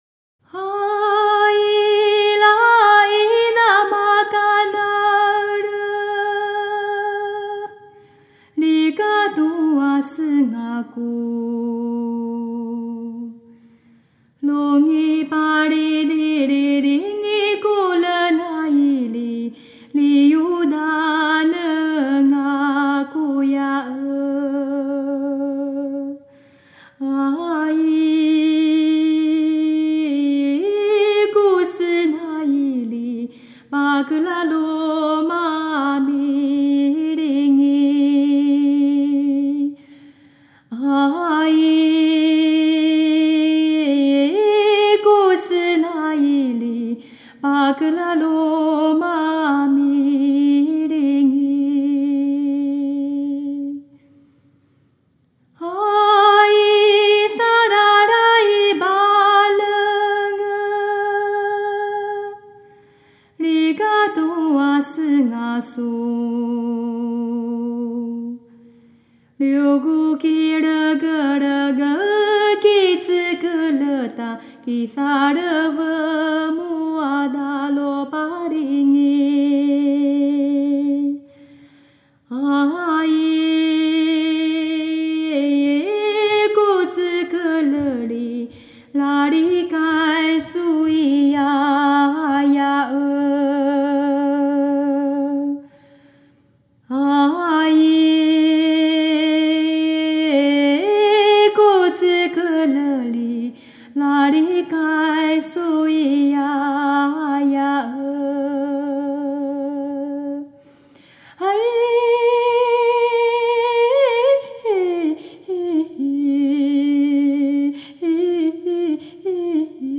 鬼湖之恋 - 鲁凯传统歌谣
這是一首鲁凯族歌谣。